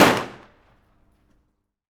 PRC PISTO02L.wav